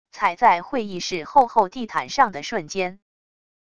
踩在会议室厚厚地毯上的瞬间wav音频